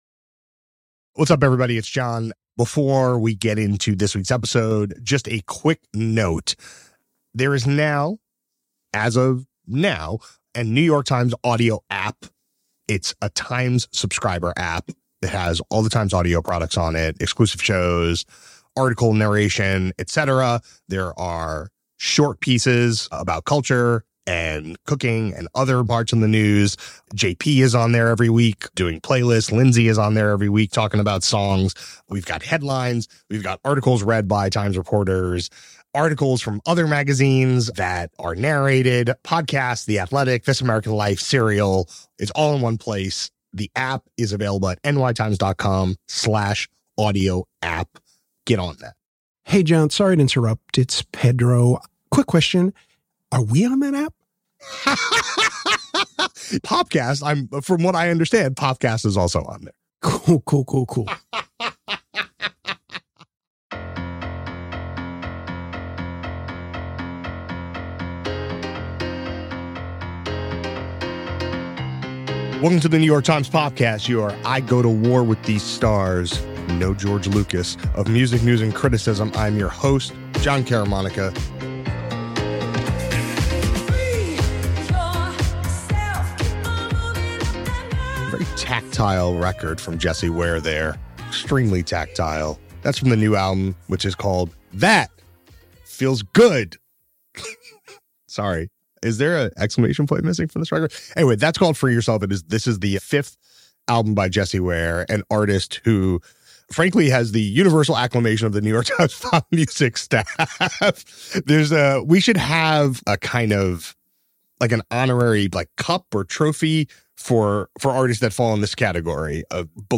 A conversation about the British musician’s journey to her lush neo-disco manifesto “That! Feels Good!”